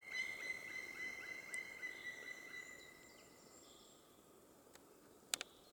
Птицы -> Дятловые ->
черный дятел, Dryocopus martius